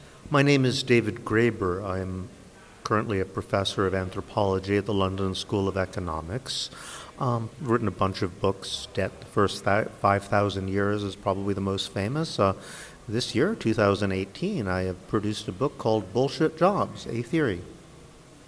David Graeber introducing himself
David_Graeber_-_voice_-_en.mp3